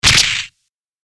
会心の一撃1.mp3